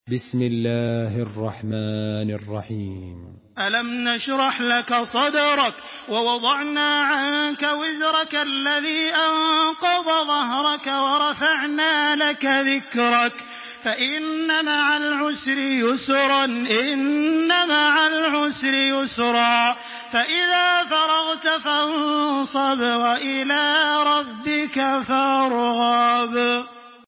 Surah আশ-শারহ MP3 by Makkah Taraweeh 1426 in Hafs An Asim narration.
Murattal Hafs An Asim